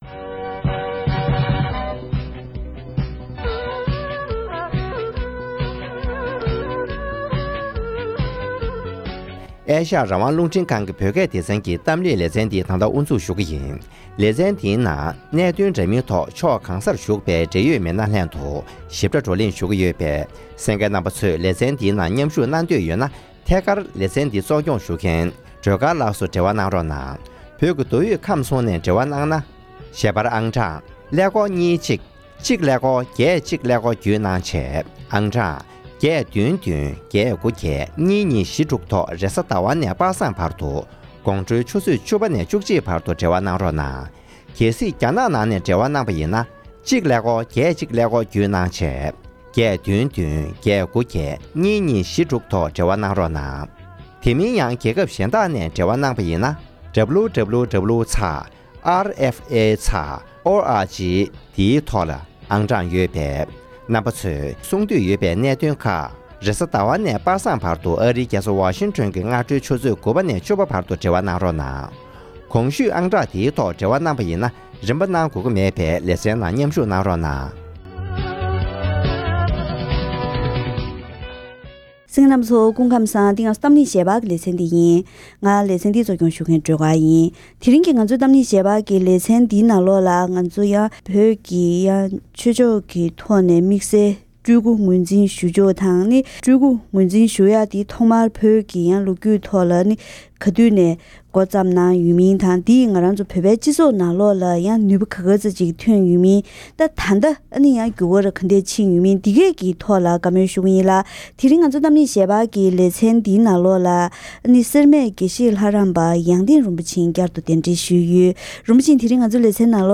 བོད་ཀྱི་སྤྲུལ་སྐུའི་ལམ་ལུགས་ཐོག་མར་བྱུང་སྟངས་དང་སྤྲུལ་སྐུའི་ལམ་ལུགས་འདིས་བོད་ཀྱི་སྤྱི་ཚོགས་ལ་ཤུགས་རྐྱེན་གང་འདྲ་བྱུང་མིན་ཐད་གླེང་མོལ།